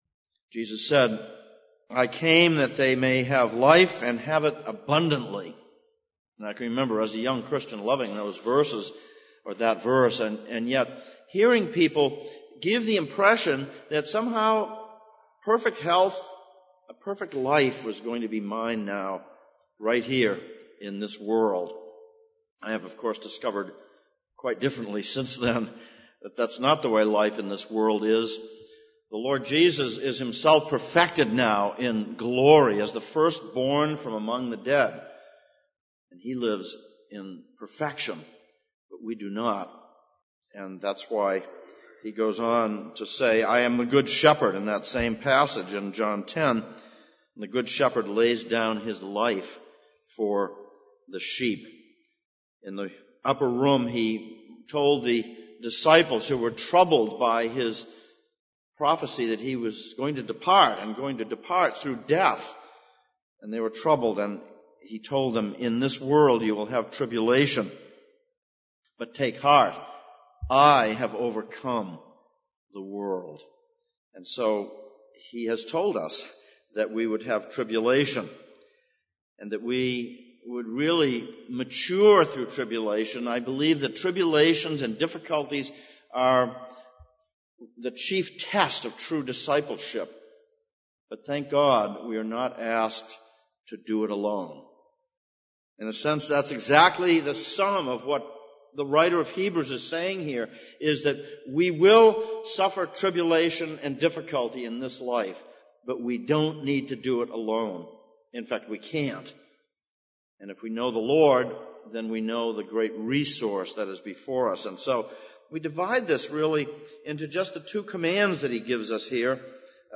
Passage: Hebrews 4:14-16, Isaiah 53:1-12 Service Type: Sunday Morning